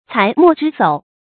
才墨之藪 注音： ㄘㄞˊ ㄇㄛˋ ㄓㄧ ㄙㄡˇ 讀音讀法： 意思解釋： 指文人聚集的地方。